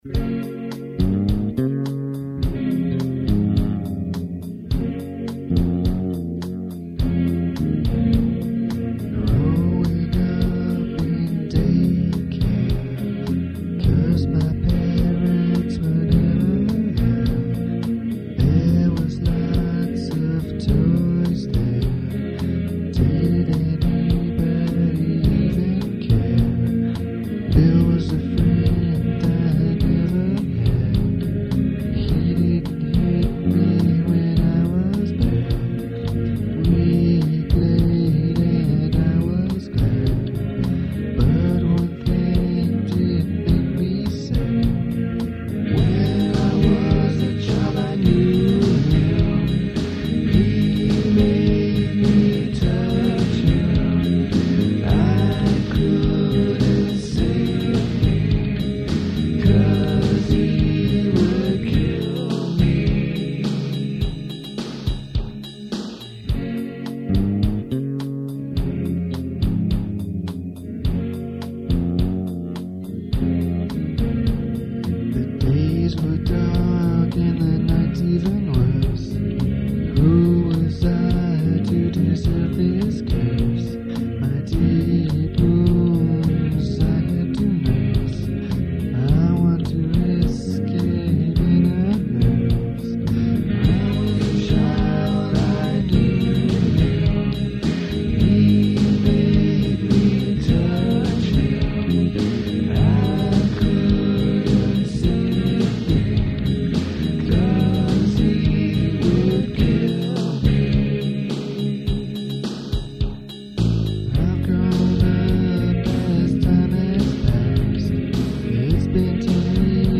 I have chosen a select few here that were recorded in the living room studio in "The Apartment" in the late '90s, around '98 or so.
These songs were written in extreme haste, recorded with more haste, with very little attention to detail, usually each instrument in one take only.
bass, backing vox